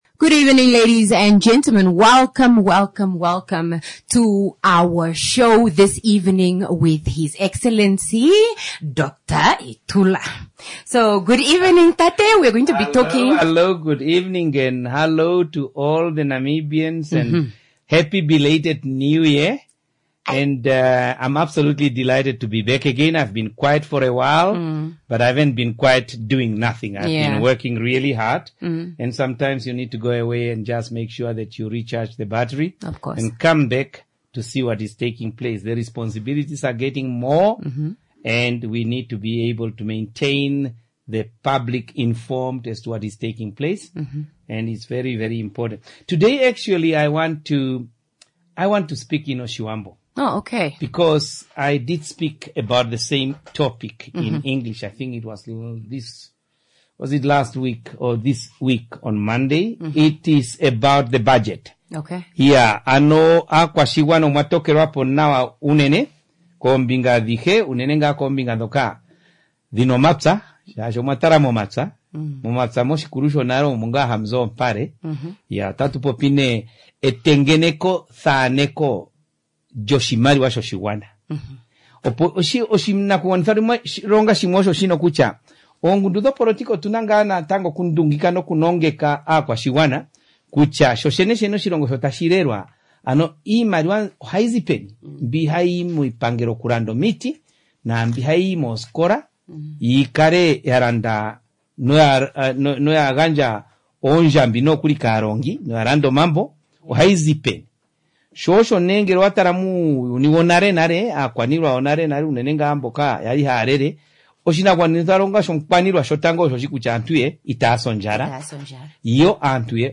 IPC INTERVIEW 6 MARCH 2026.mp3